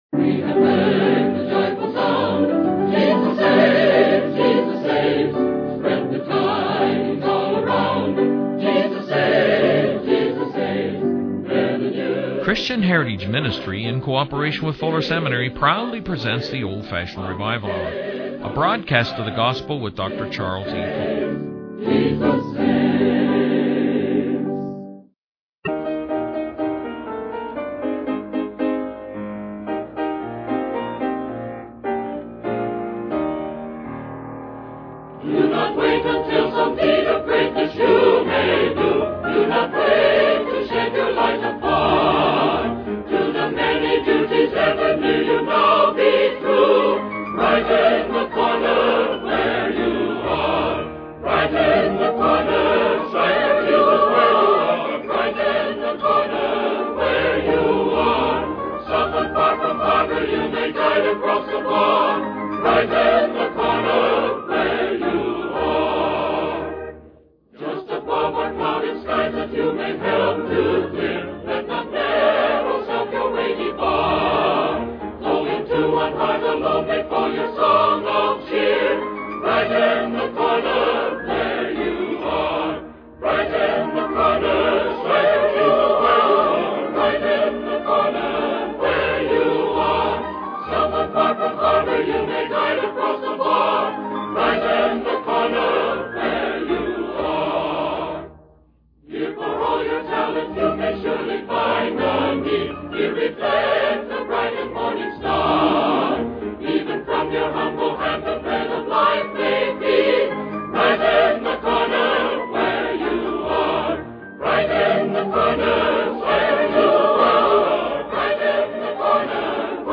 The video is a sermon transcript that begins with a young boy expressing his gratitude for being a Christian and his desire for his father to be saved. The sermon then transitions to the story of Job, highlighting the series of tragedies that befell him, including the loss of his possessions, the death of his children, and the destruction of his home.